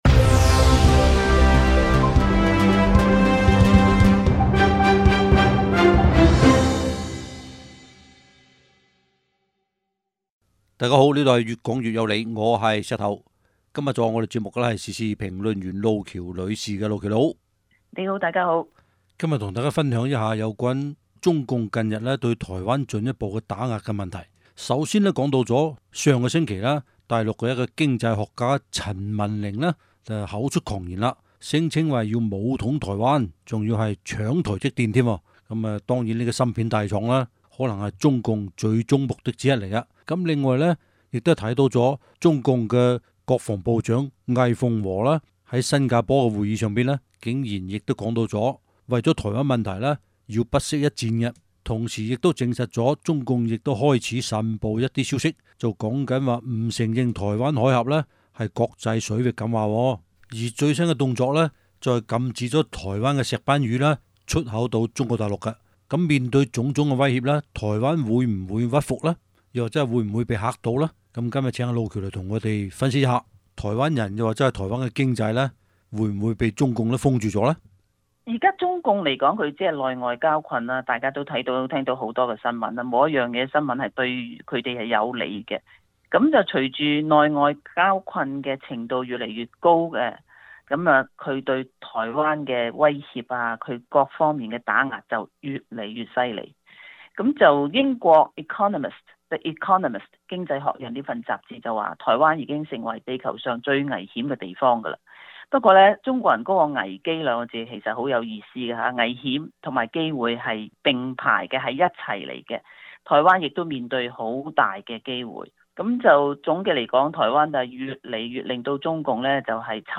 時事評論